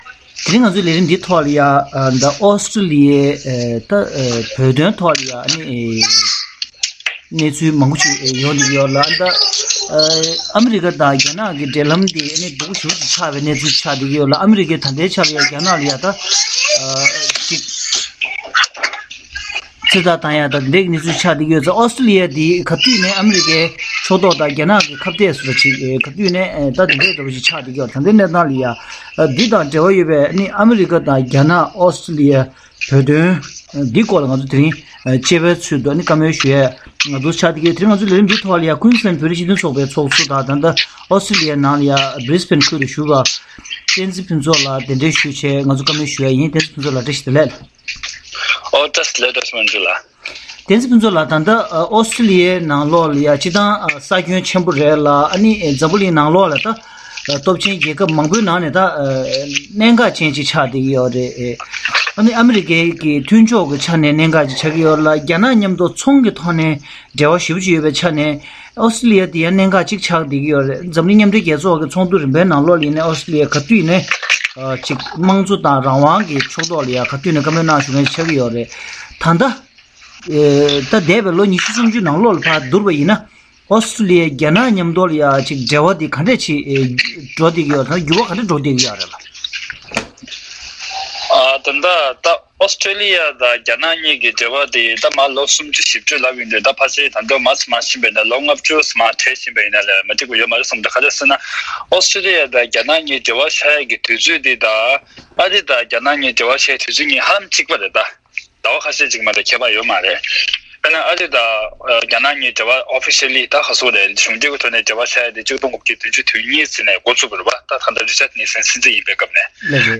རྒྱ་ནག་ལ་འཛམ་གླིང་རྒྱལ་ཁབ་མང་པོས་སྐྱོན་བརྗོད་བྱེད་བཞིན་ཡོད་པ་དང་ཨོས་ཀྲེ་ལི་ཡ་དང་རྒྱ་ནག་དབར་གྱི་འབྲེལ་བའི་ཐོག་ནས་བོད་དོན་ལ་ཤུགས་རྐྱེན་ཐད་གླེང་མོལ།